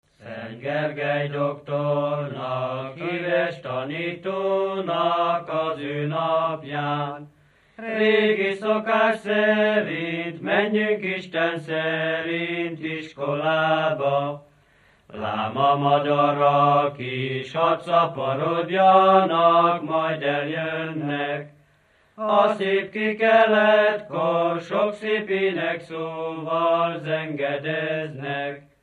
Dunántúl - Zala vm. - Göntérháza
Műfaj: Gergelyjárás
Stílus: 7. Régies kisambitusú dallamok